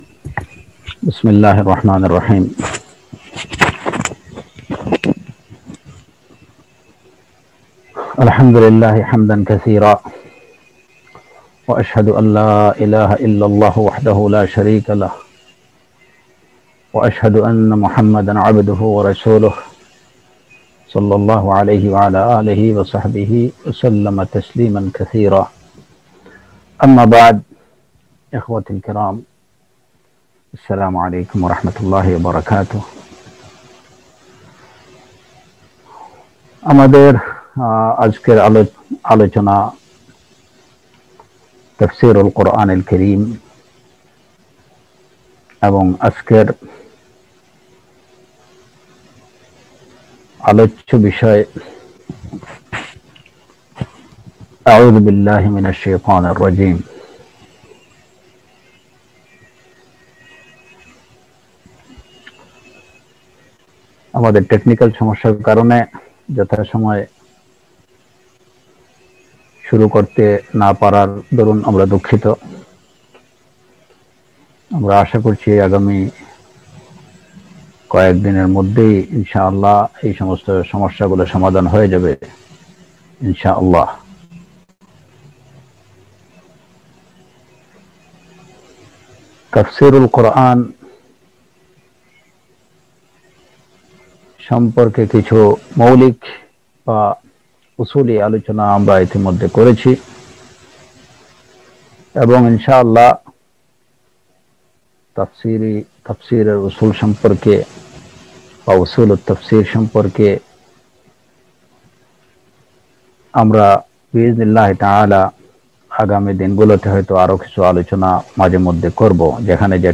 প্রদত্ত একটি ধারাবাহিক অডিও বক্তব্য।